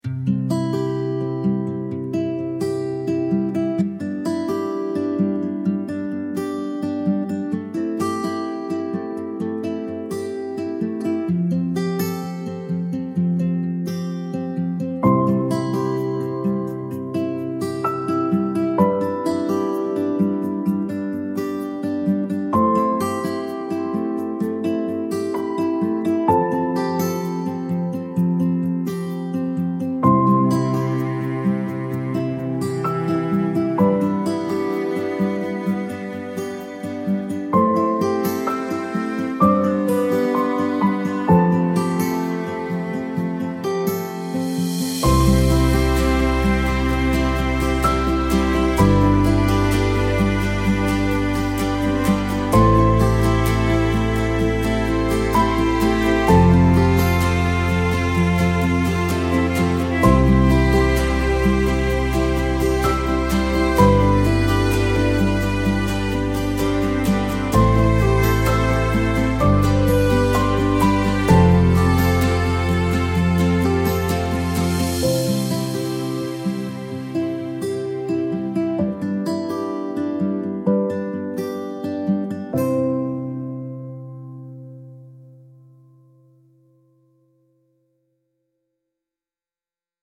enduring melodic piece with acoustic instruments evoking lasting love